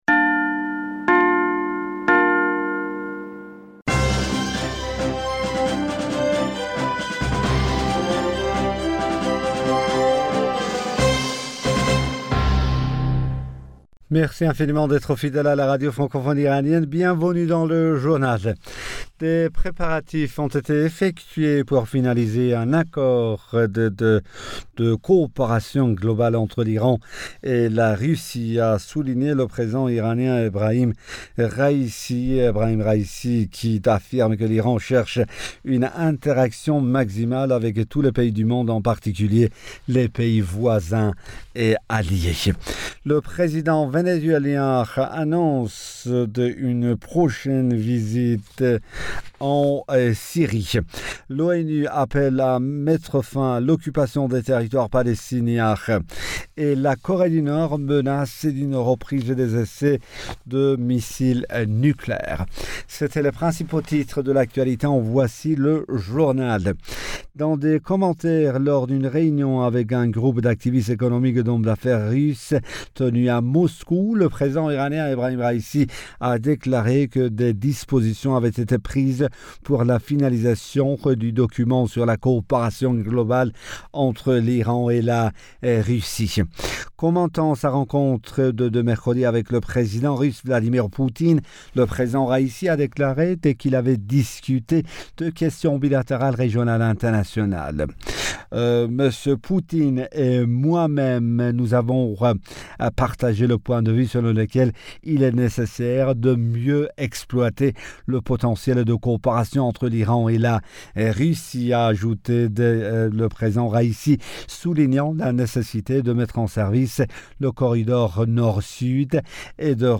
Bulletin d'information Du 21 Janvier 2022